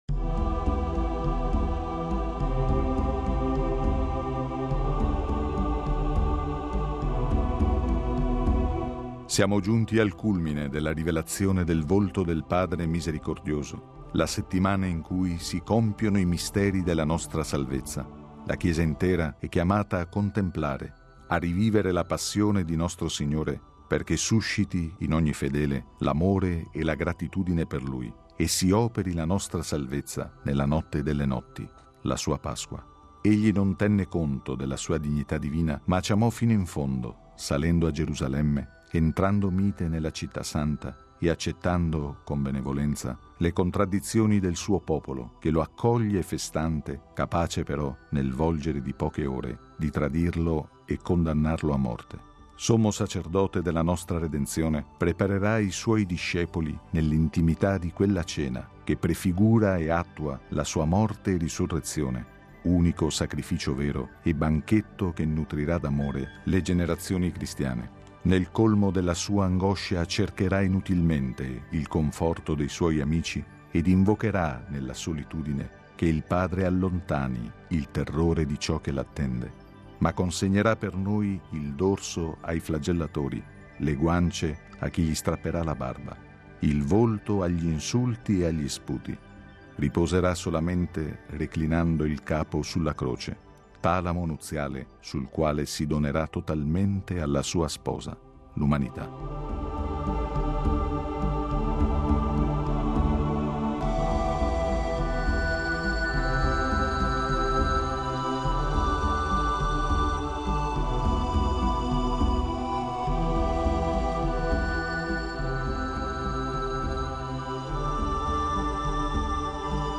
Il commento